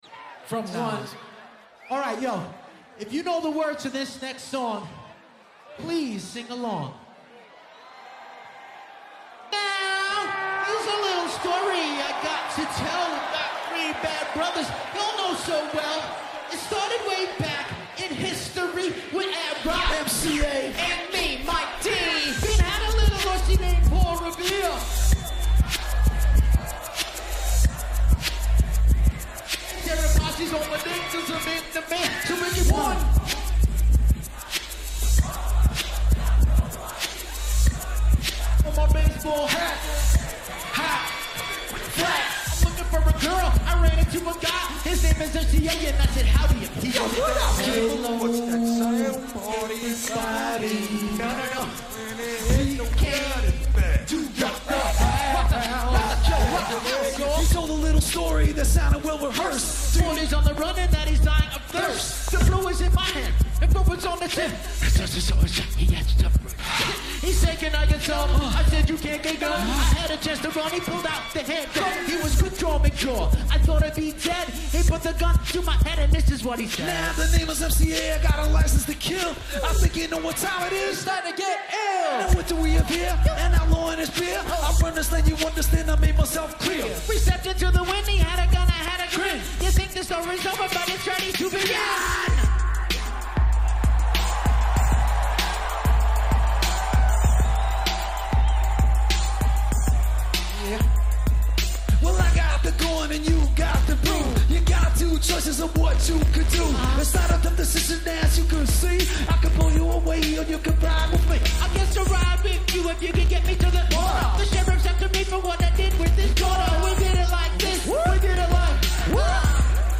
at their final show at Bonnaroo 2009